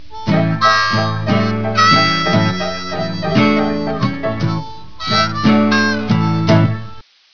Blues